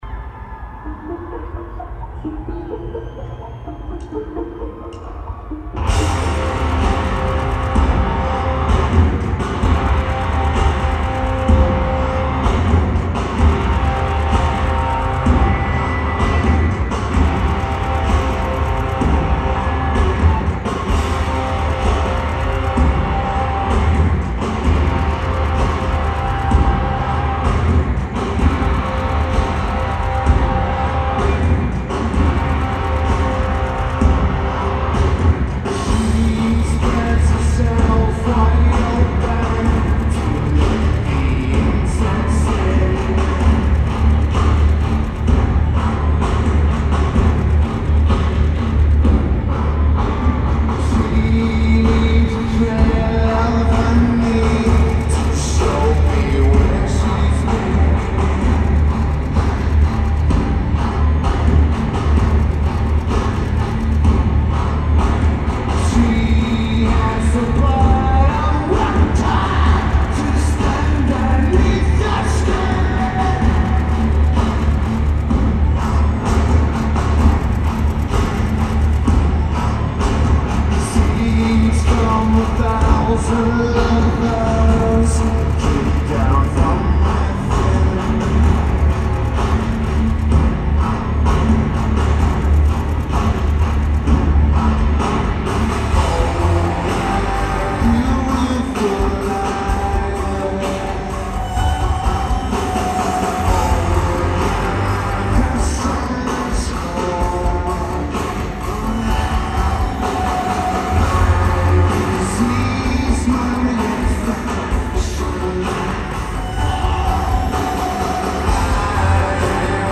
Rose Garden Arena
Lineage: Audio - AUD (CSB's + Sharp MD- MT15)
Notes: Recorded from Section 121, row K, seat 13.